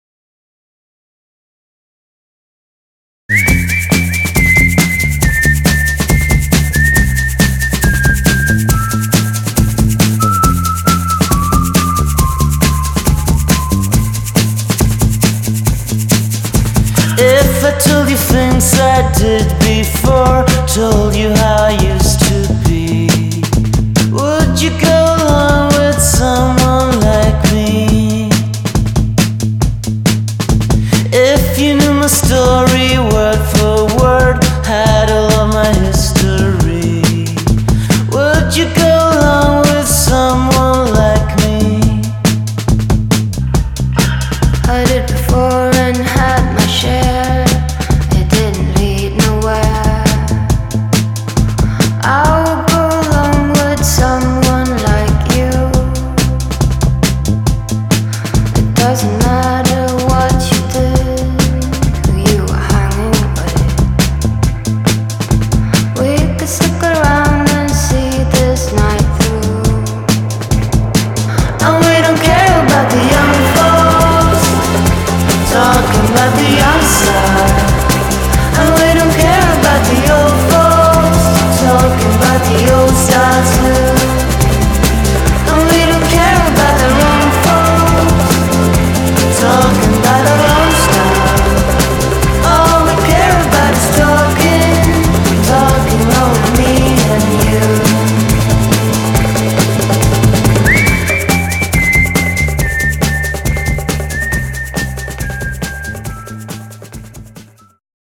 BPM138
Audio QualityPerfect (High Quality)